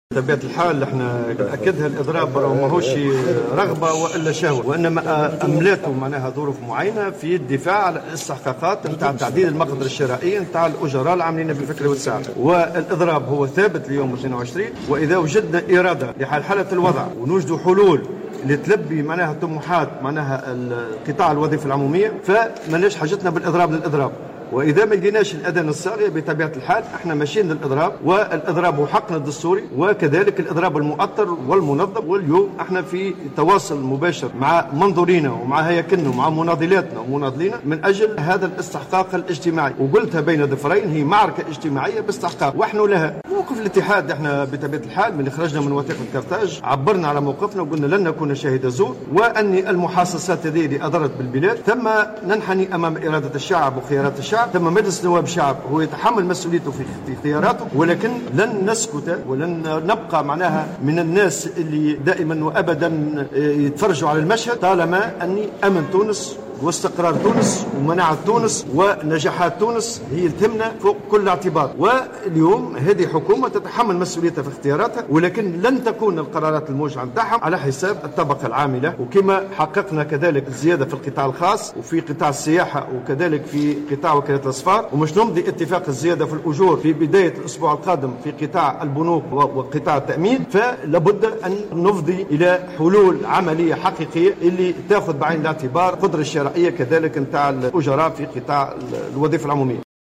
أكد الامين العام للاتحاد العام التونسي للشغل نور الدين الطبوبي، في تصريح إعلامي عقب تجمع عمالي امام مقر الاتحاد الجهوي للشغل بصفاقس، أن الاضراب المقرّر في الوظيفة العمومية يوم 22 نوفمبر الحالي مازال ثابتا في حال لم يجد الاتحاد ارادة من الطرف الحكومي لإيجاد الحلول التي تلبي طموحات.